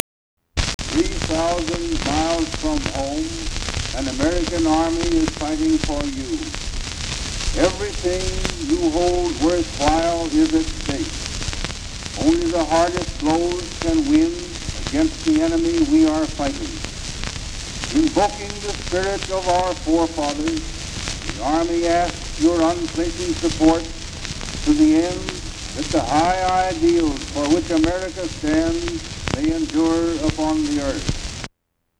Recorded by Nation's Forum, 1917.